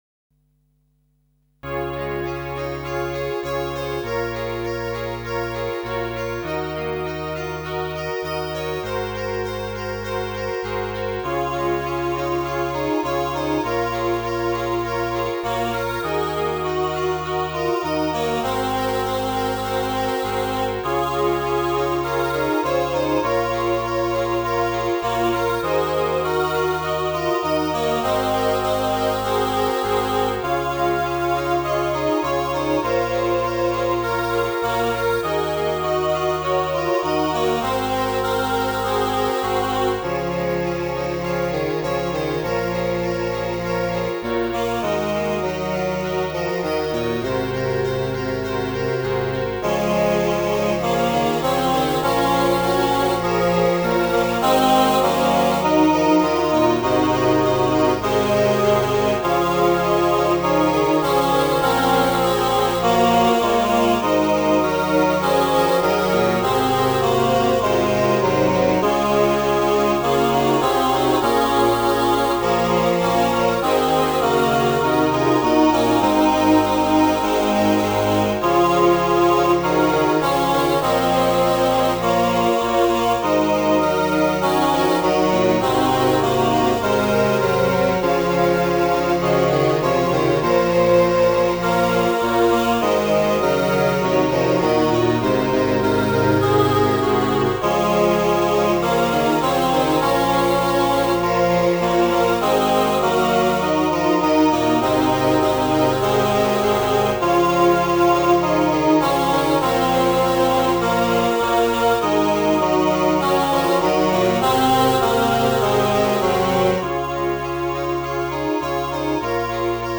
I will give my life Choir (Words)